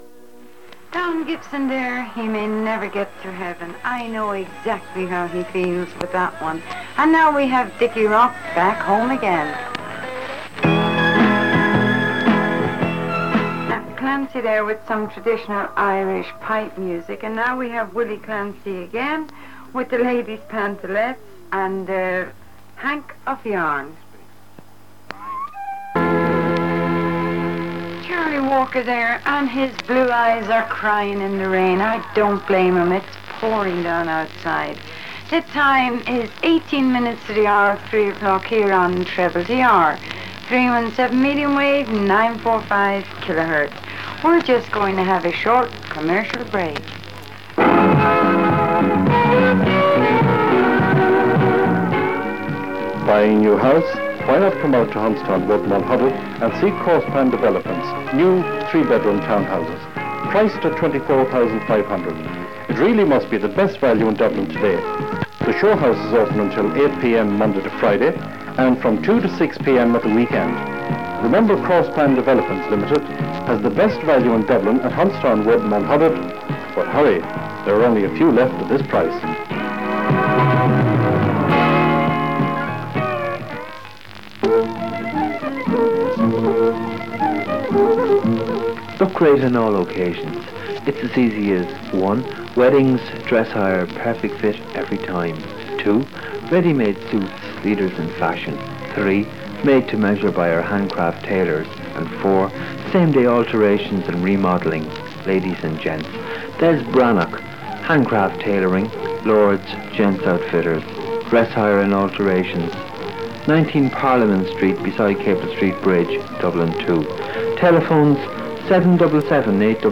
This recording of TTTR at Christmas 1981 features two unidentified DJs playing country tunes, interspersed with adverts for businesses in Dublin and surrounding areas.
Audio quality is fair due to a combination of a weak AM signal, cassette degradation and co-channel interference on the frequency due to the time of year.